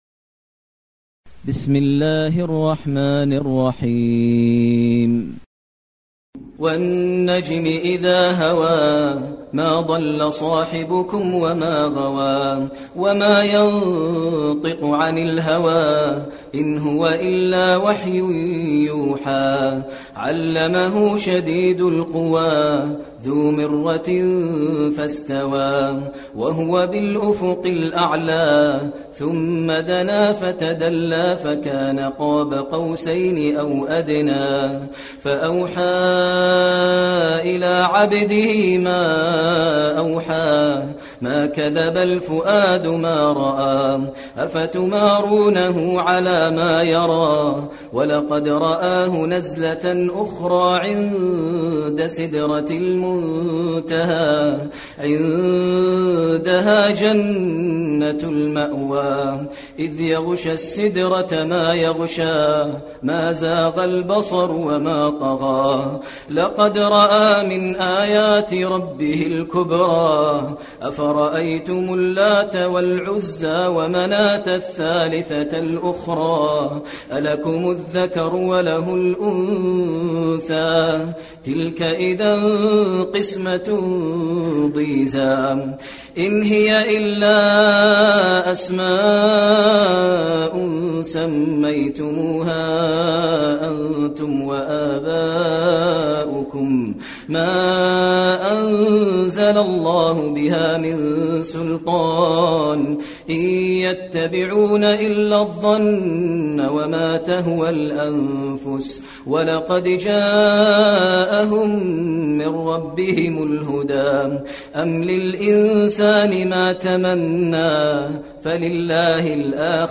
المكان: المسجد النبوي النجم